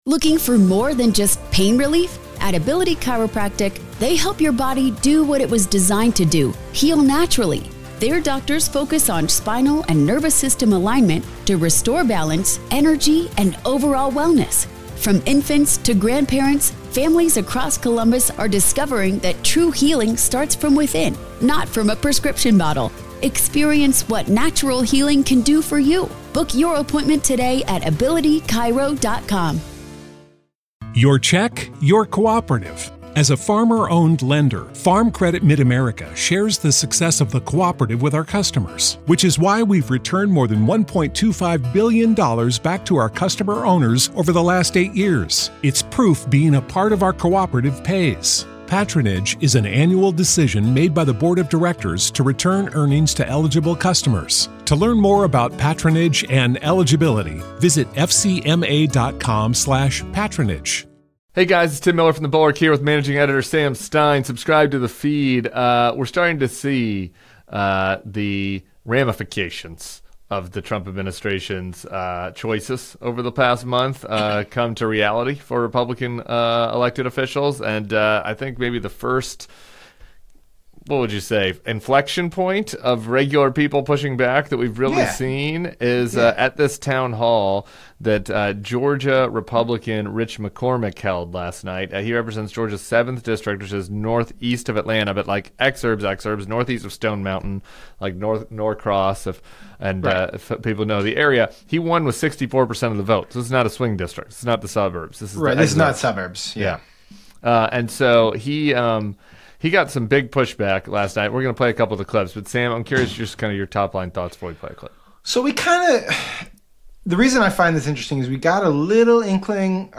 GOP Rep. McCormick faces a raucous crowd at his townhall after a packed house pushes back against the Trump administration.